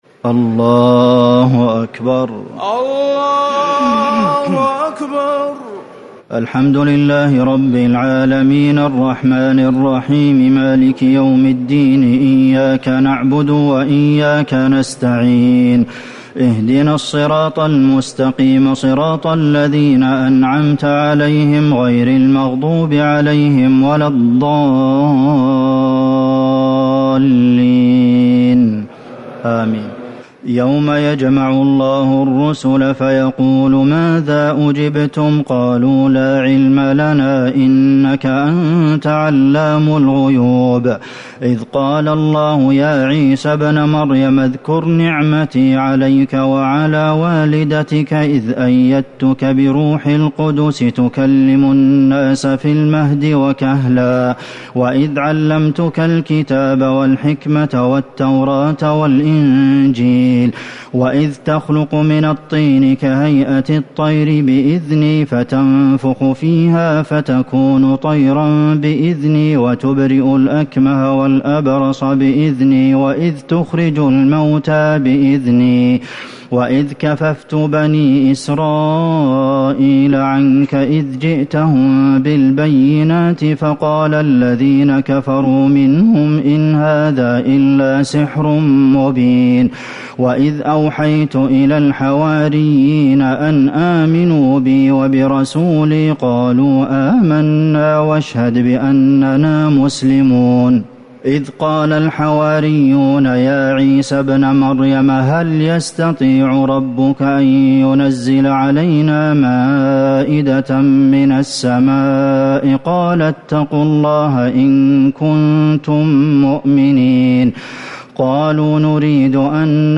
ليلة ٦ رمضان ١٤٤٠ من سورة المائدة ١٠٩ - الانعام ٧٣ > تراويح الحرم النبوي عام 1440 🕌 > التراويح - تلاوات الحرمين